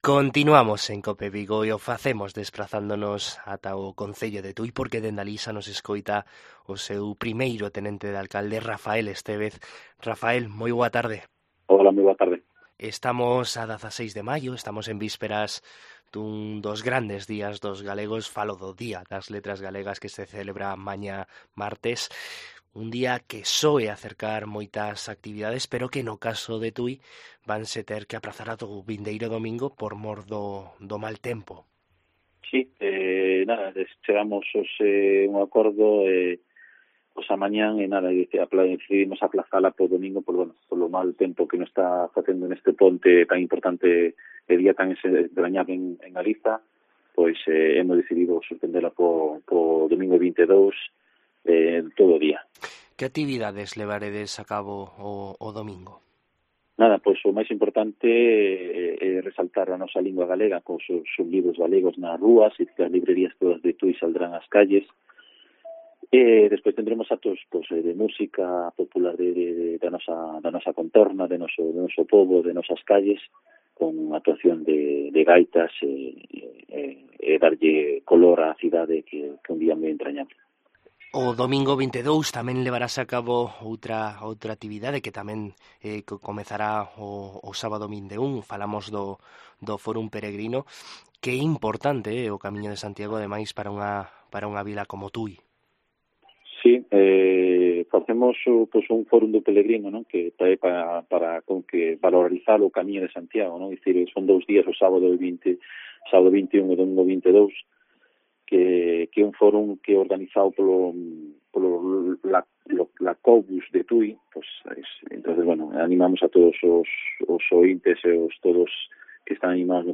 En COPE Vigo hablamos con el primer teniente de alcalde de Tui, Rafael Estévez, para conocer la actualidad de esta localidad del sur de la provincia de Pontevedra